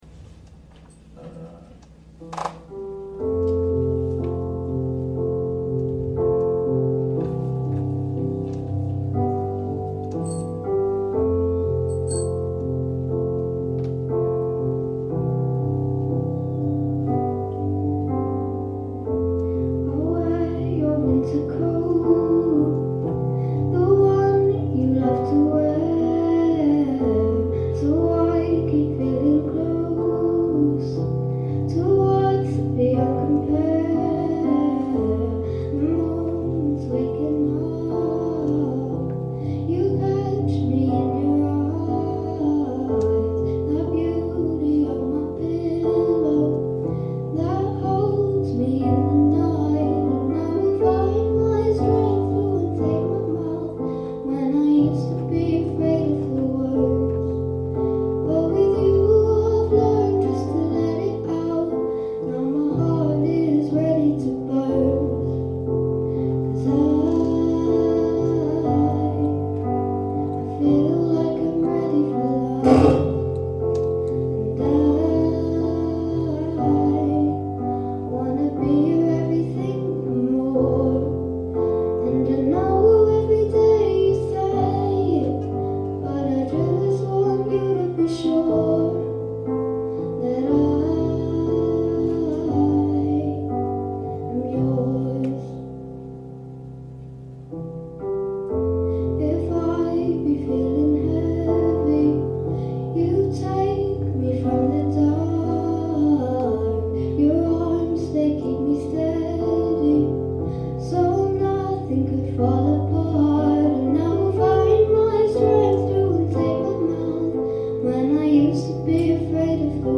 Singing Evening Nov 21